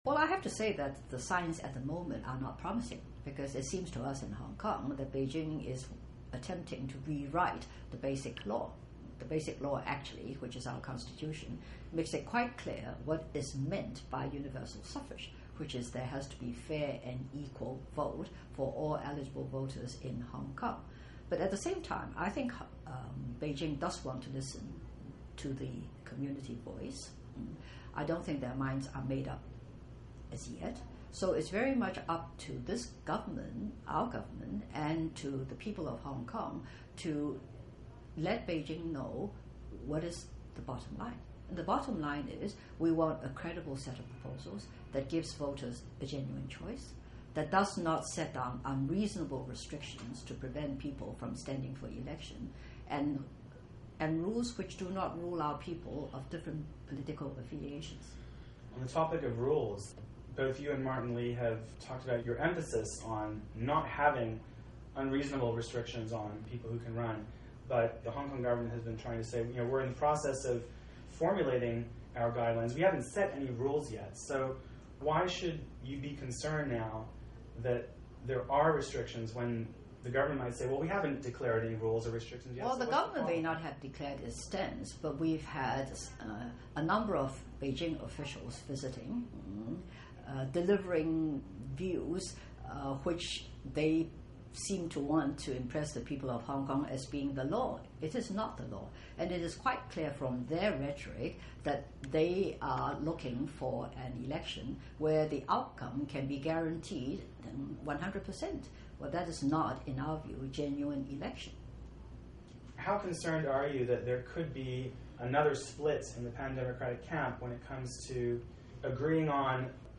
Q&A with Anson Chan